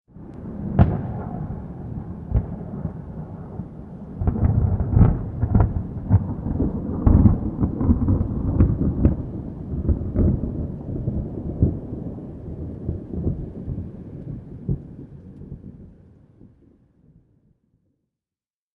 جلوه های صوتی
دانلود آهنگ طوفان 10 از افکت صوتی طبیعت و محیط
دانلود صدای طوفان 10 از ساعد نیوز با لینک مستقیم و کیفیت بالا